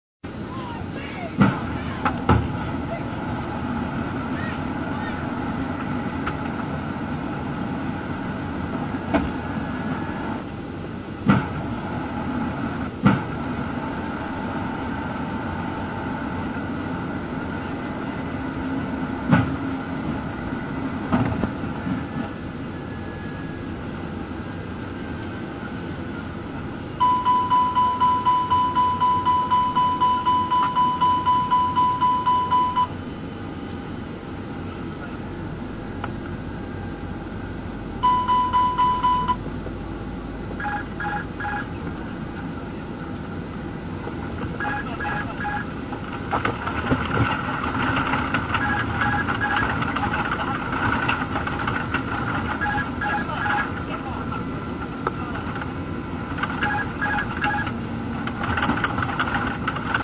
traccia audio (ora rimossa), pubblicata sul portale You-tube e che è stato dichiarato essere la registrazione degli ultimi 60 secondi del volo 9525. Il file audio risulta essere frutto di manipolazione. Alcune parti di quelli che sono spacciati per i suoni in cabina sono ripetute più volte, mentre alla fine è circostanza anomala non udire il sistema di bordo richiamare il pilota con l'avviso "Pull up".